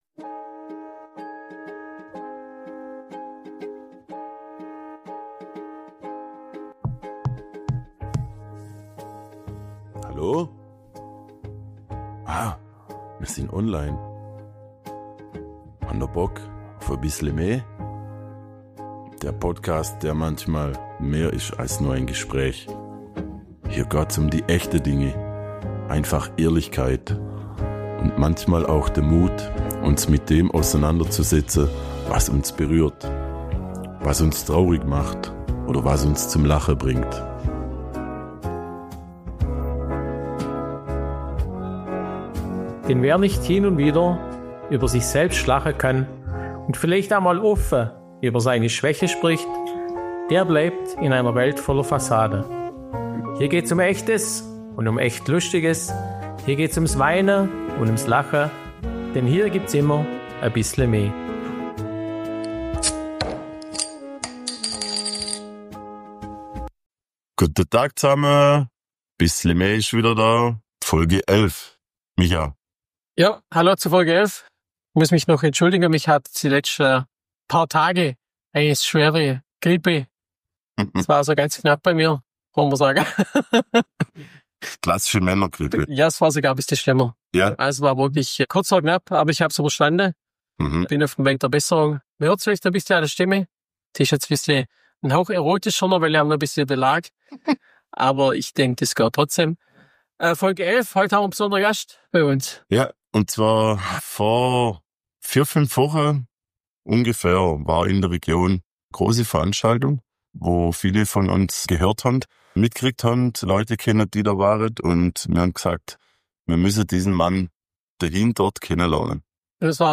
#11 Geburtstag gone wild: Das Bergfest-Festival ~ Bissle me – Schwoba-Podcast aus´m Schlofsack Podcast
In dieser Folge von Bissle Me wird’s laut, lustig und ein bisschen verrückt.